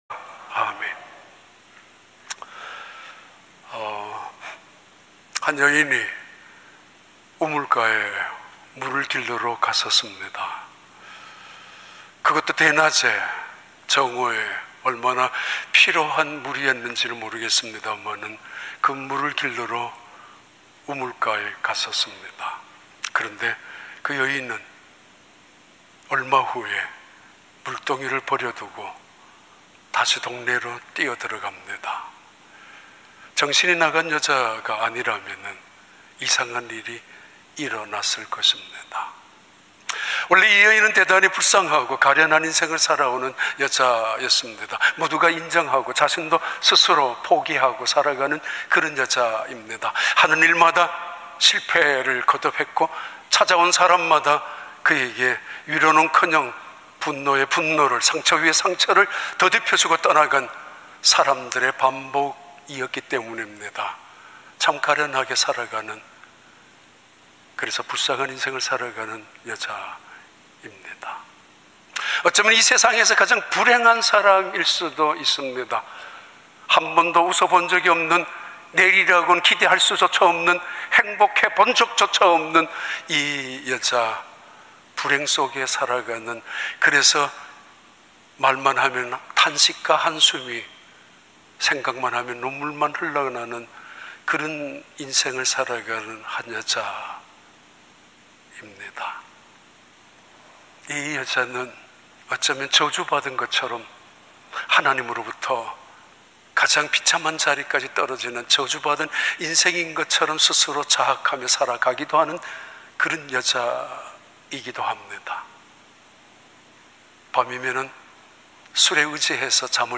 2022년 7월 10일 주일 3부 예배